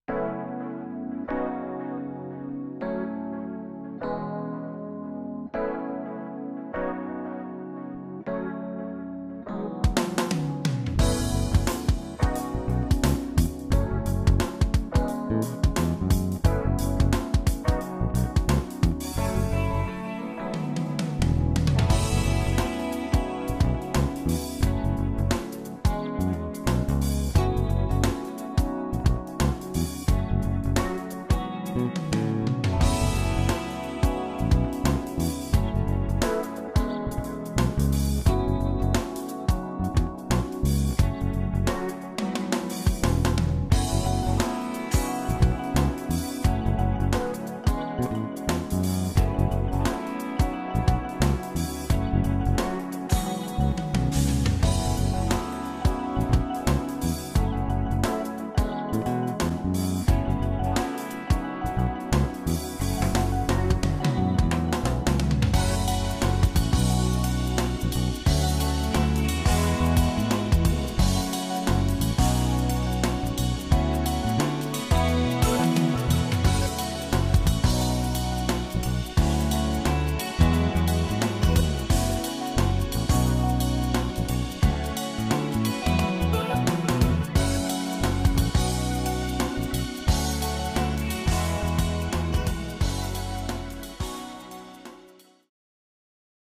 Ohranejsi struny